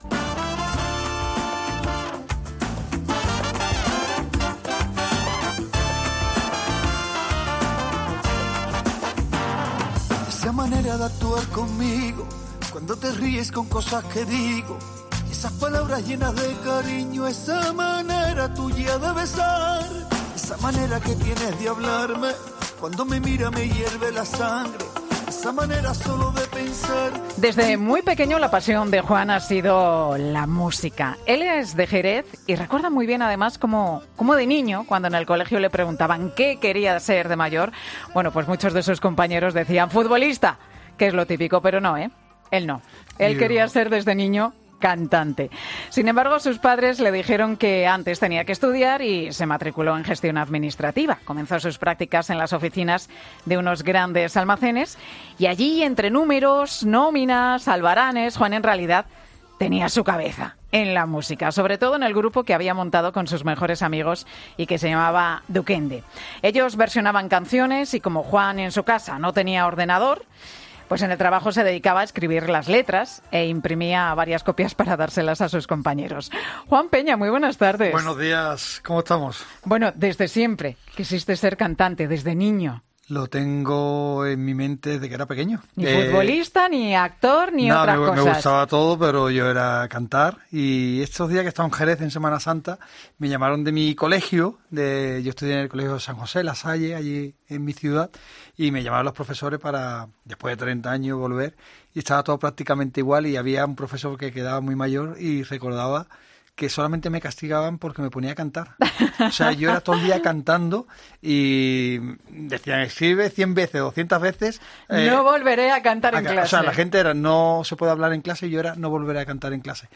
Juan Peña sorprende a Pilar García Muñiz cantando a capela: "Va a ser increíble"
Juan Peña canta en Ecclesia al Día